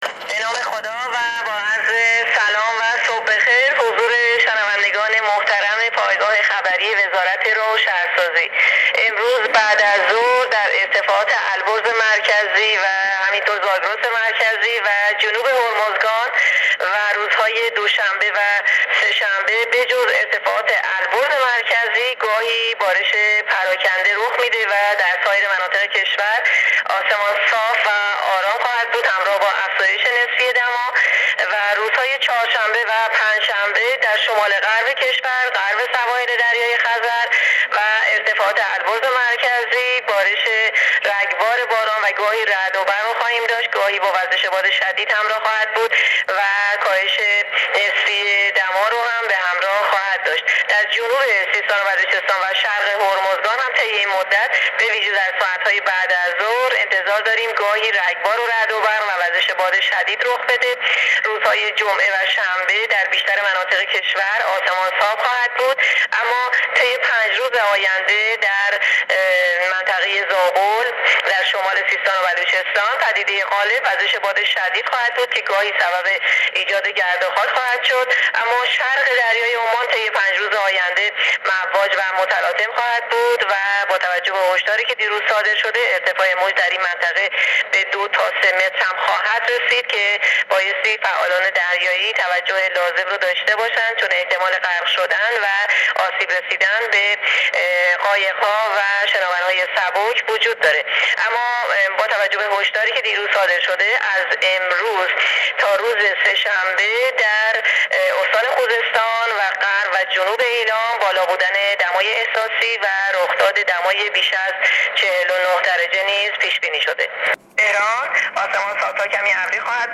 گزارش رادیو اینترنتی از آخرین وضعیت آب و هوای ۲۹ تیر